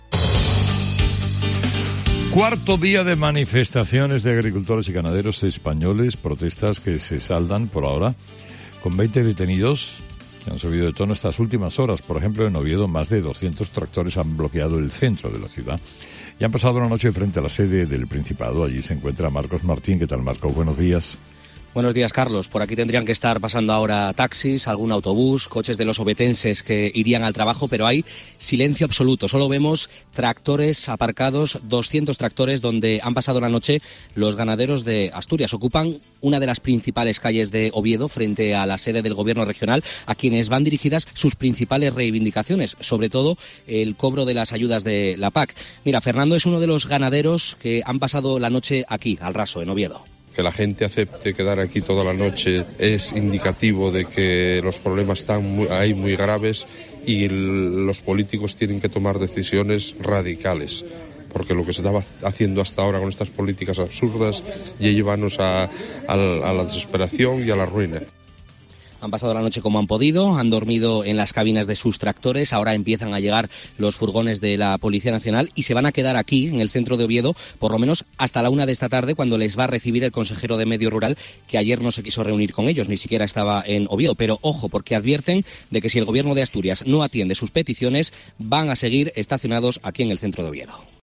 COPE cuenta, desde la calle Uría de la capital del Principado, cómo está siendo la tractorada y los agricultores advierten: "Si no aceptan nuestras peticiones, seguiremos aquí"